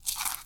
Crunch Bite Item (6).wav